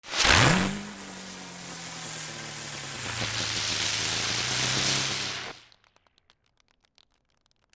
花火 地上回転花火A1
導火線～着火R26